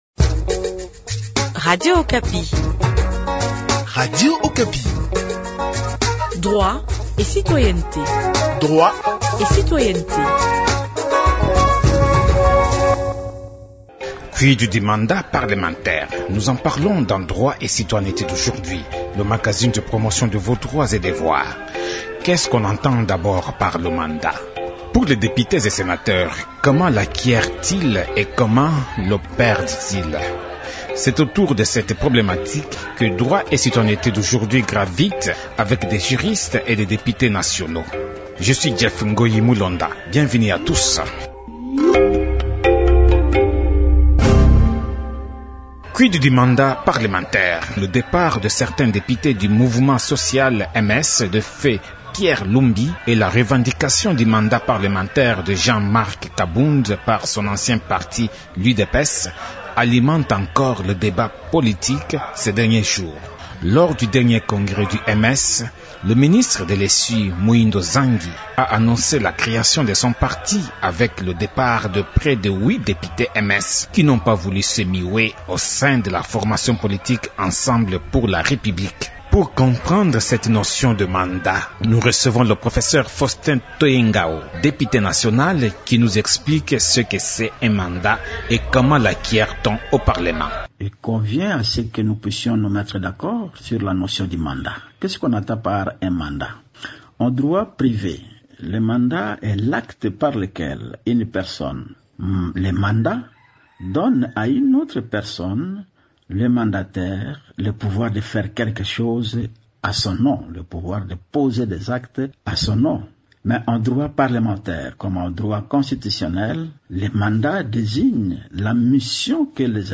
Pour les députés et sénateurs, comment l’acquièrent-ils et comment le perdent-ils ? C’est autour de cette problématique que gravite ce magazine avec des juristes et des députés nationaux.